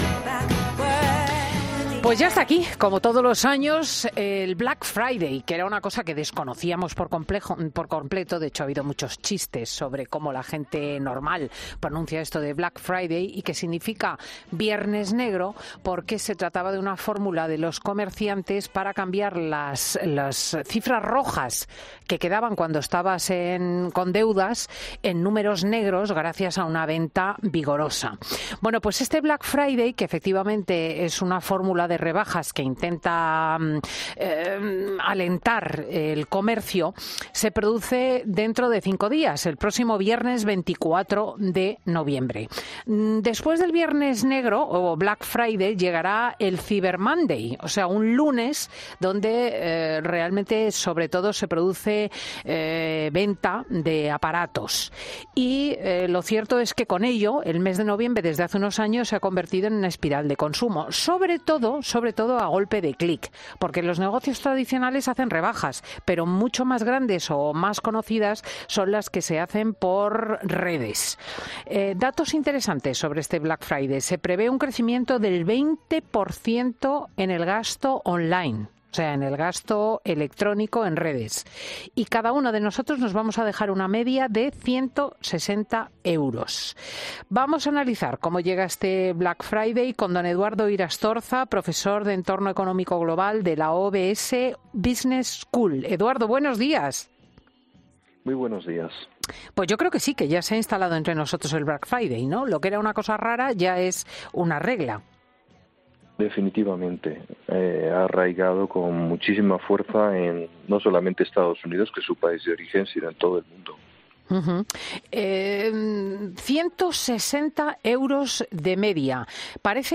Vamos a analizar cómo viene este Black Friday en 'Fin de Semana' con un experto.